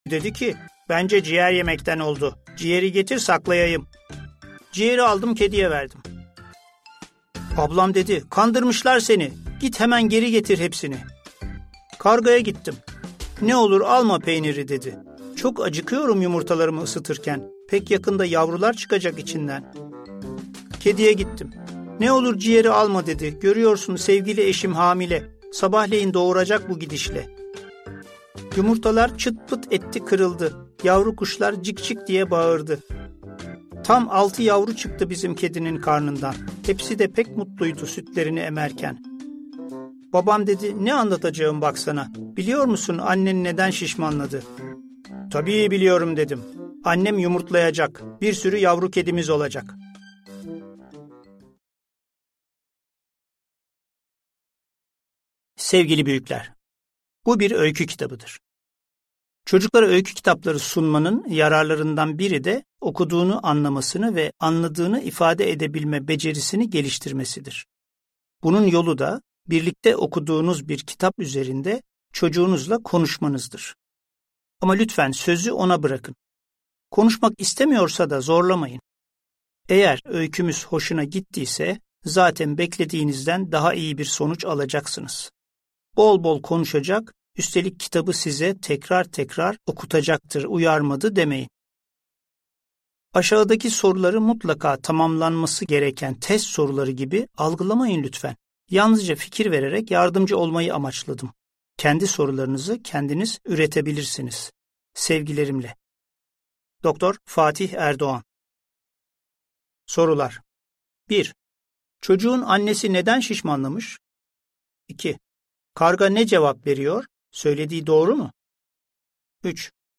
Fatih Erdoğan’ın Annem Neden Şişmanladı? kitabını yazarın sesinden dinleyin.